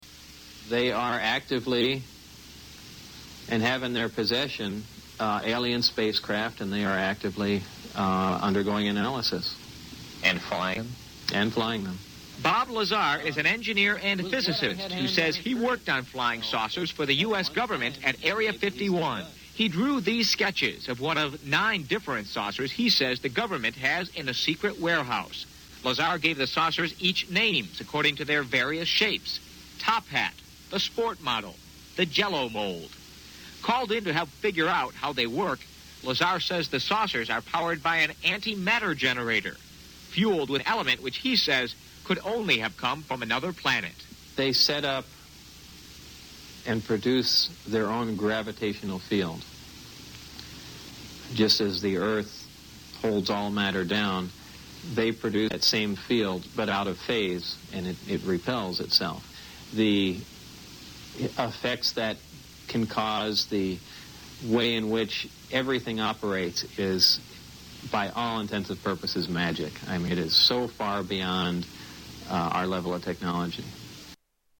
A News interview with Bob Lazar